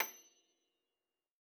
53j-pno28-F6.aif